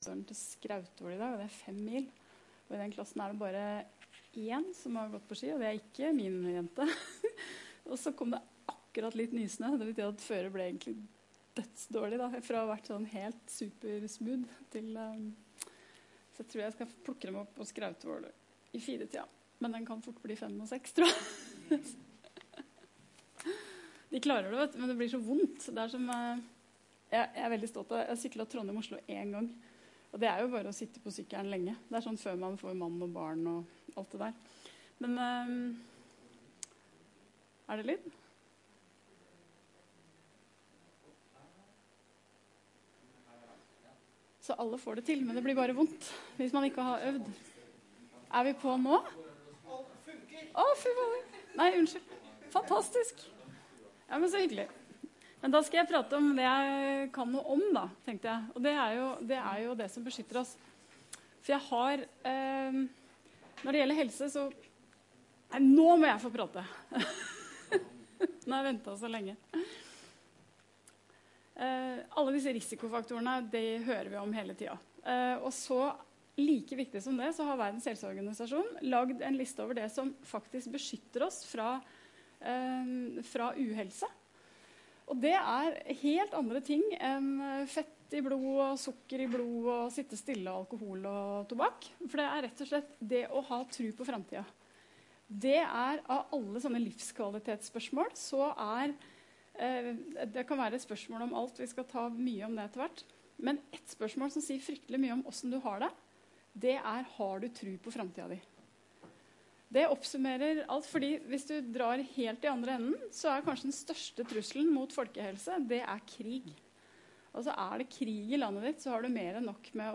Universell utforming i et planperspektiv og i relasjon til folkehelse - NTNU Forelesninger på nett